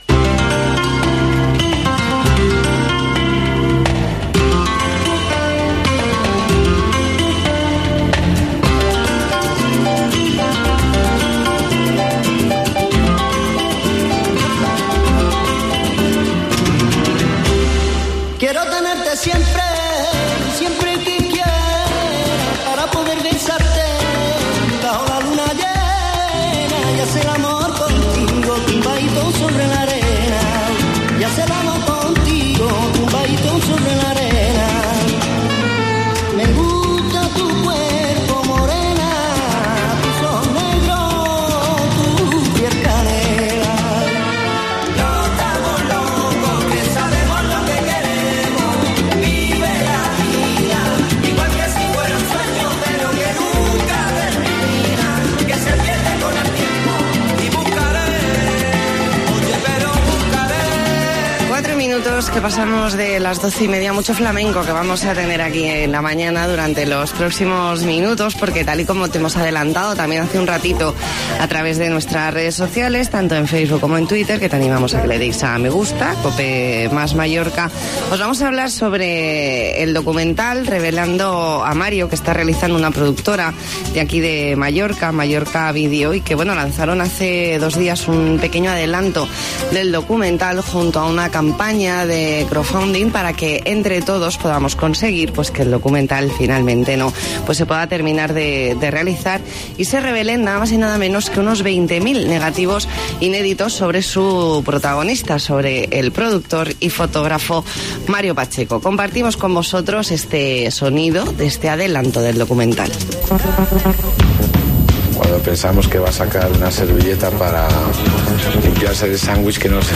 Entrevista en La Mañana en COPE Más Mallorca, miércoles 12 de junio de 2019.